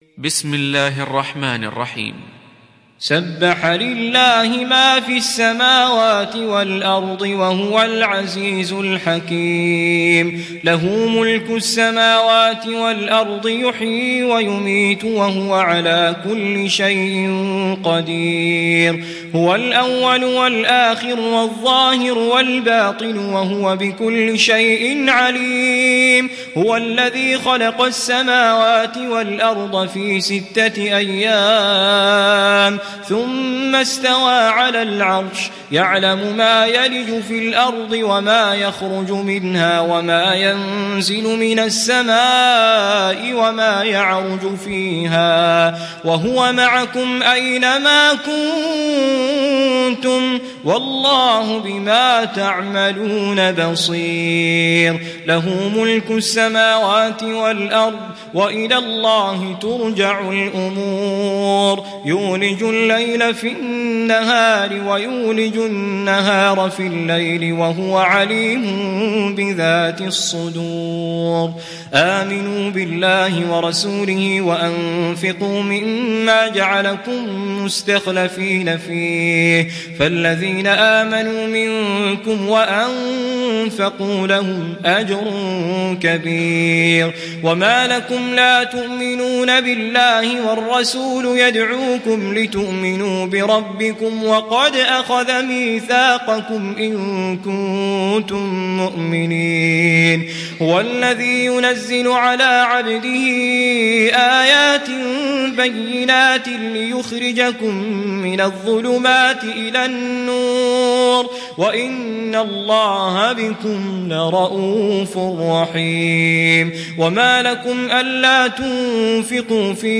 تحميل سورة الحديد mp3 بصوت توفيق الصايغ برواية حفص عن عاصم, تحميل استماع القرآن الكريم على الجوال mp3 كاملا بروابط مباشرة وسريعة